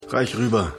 Synchronstudio: FFS Film- & Fernseh-Synchron GmbH